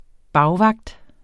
Udtale [ ˈbɑwˌvɑgd ]